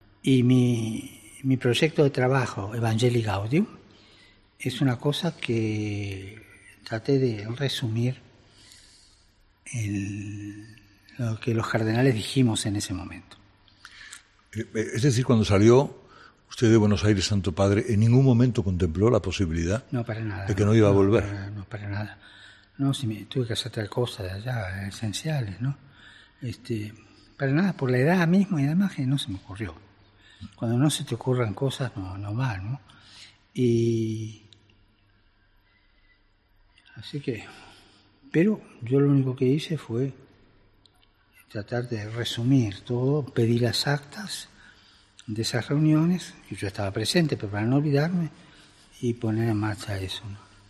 Este miércoles, 1 de septiembre, hemos escuchado, en exclusiva, la primera entrevista que el Papa Francisco ha concedido a una radio española y la primera tras su operación